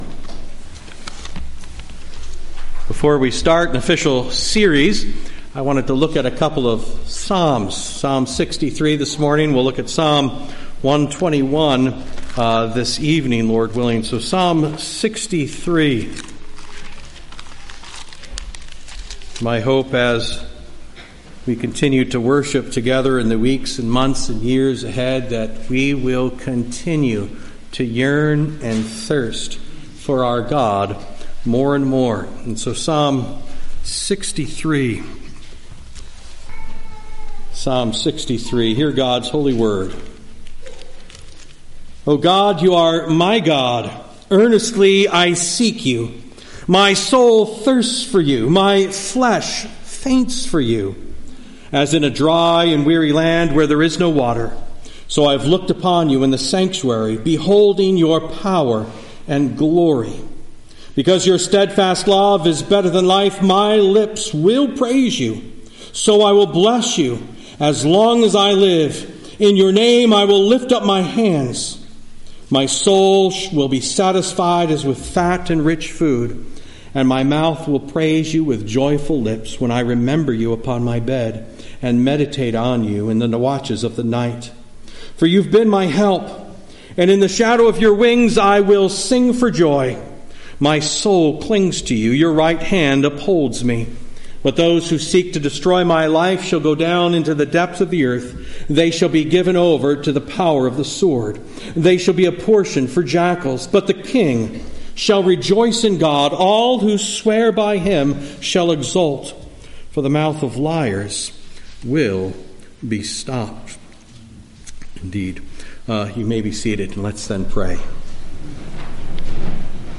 Posted in Sermons , Sermons 2025